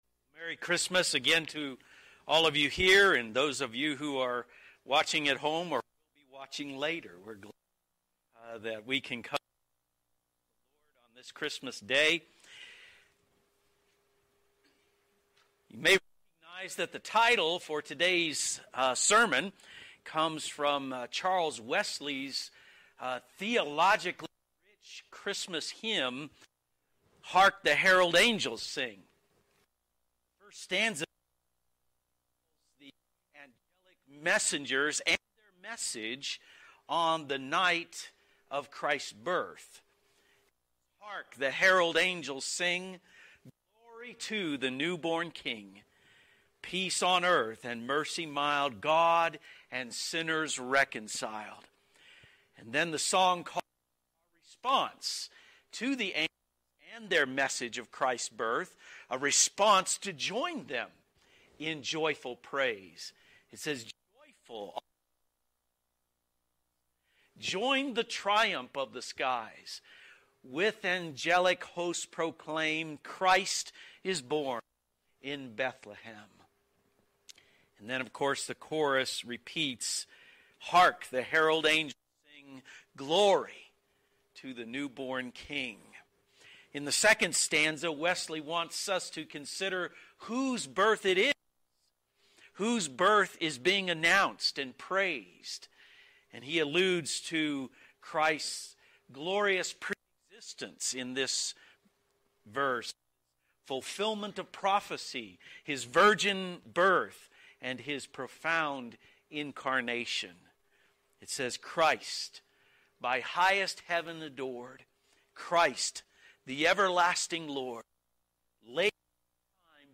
You may recognize that the title for today’s sermon comes from Charles Wesley’s theologically rich Christmas hymn, Hark the Herald Angels Sing.